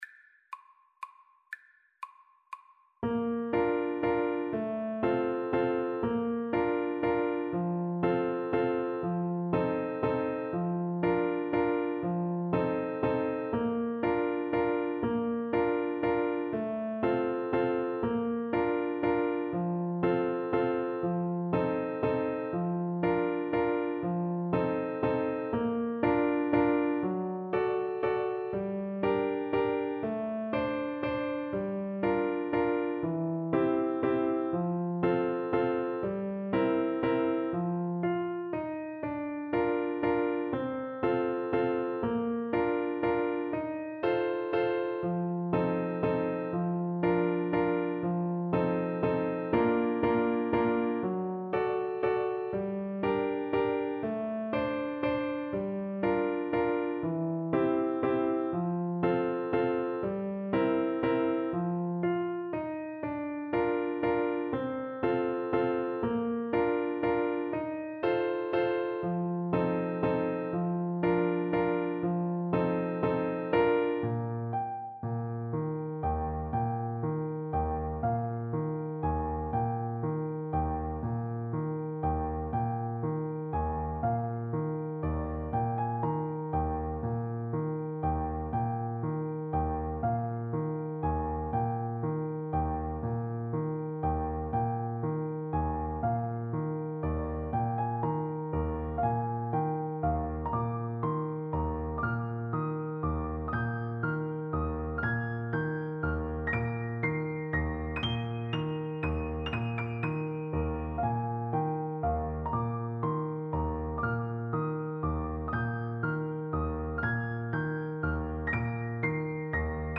Play (or use space bar on your keyboard) Pause Music Playalong - Piano Accompaniment Playalong Band Accompaniment not yet available transpose reset tempo print settings full screen
3/4 (View more 3/4 Music)
Bb major (Sounding Pitch) F major (French Horn in F) (View more Bb major Music for French Horn )
Classical (View more Classical French Horn Music)